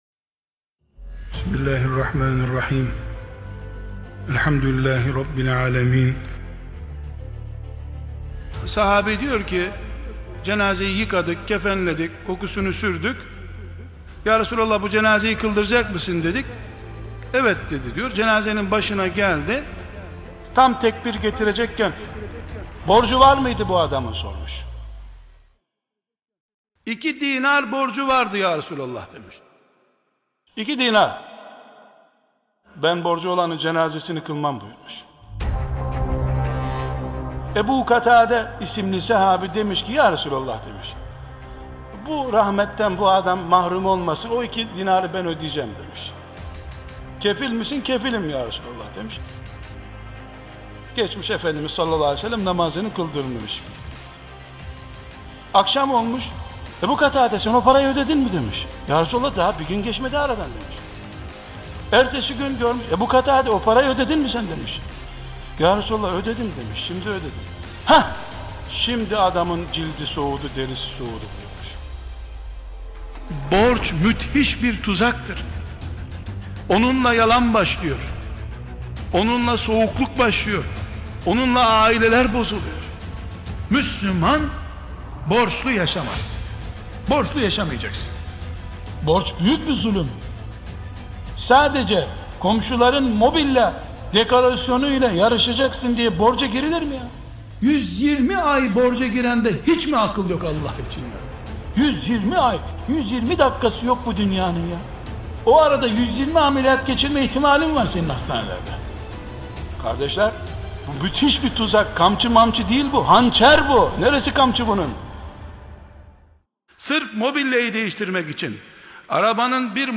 1. Sohbet Arşivi
2. Efektli Videolar (Gönüllü Çalışmaları)